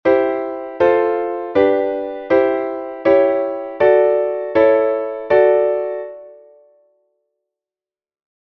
2. C-Dur zu D-Dur
Das Prinzip ist dasselbe, nur die Zieltonart ist hier D-Dur statt d-Moll.
Chromatische-Modulation-C-Dd.mp3